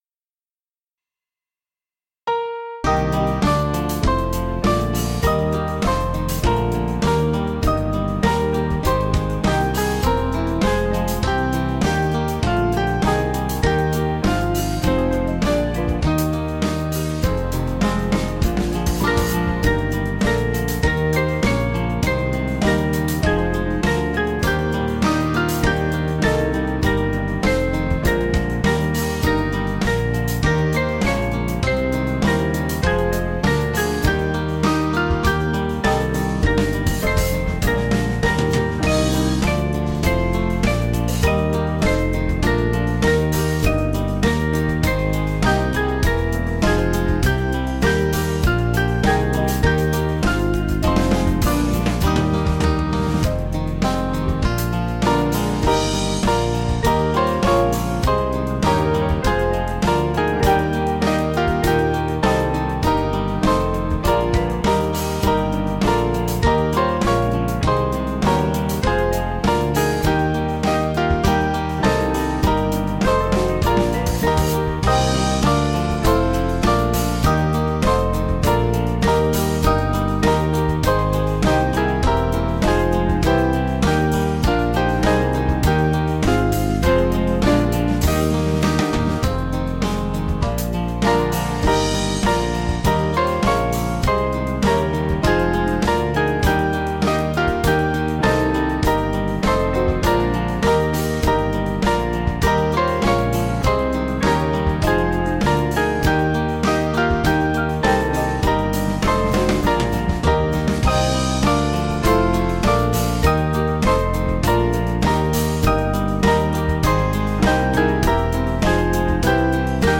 Small Band
(CM)   3/Eb 481.7kb